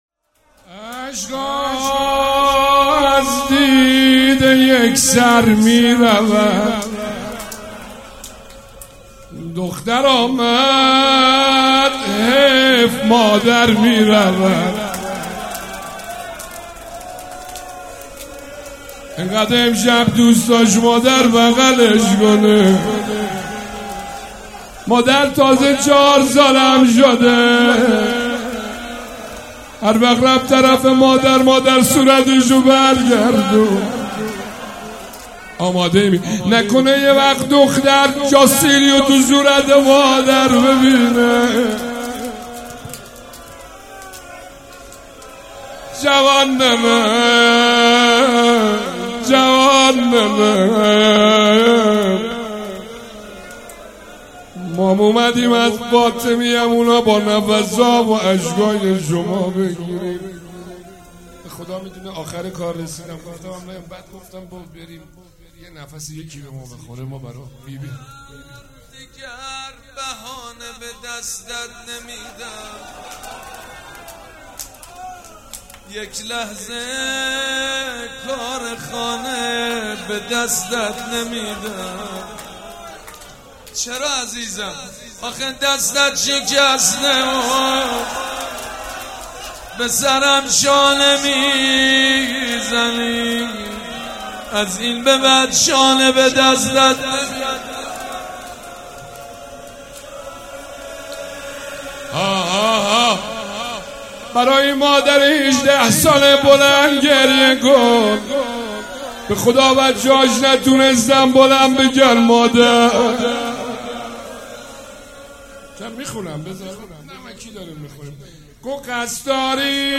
روضه بخش دوم
هیئت ریحانه الحسین سلام الله علیها
سبک اثــر روضه